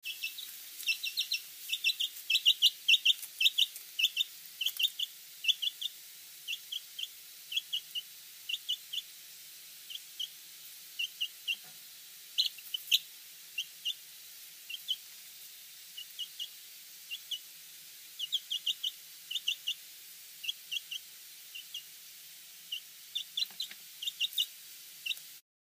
Chick Chirping Téléchargement d'Effet Sonore
Chick Chirping Bouton sonore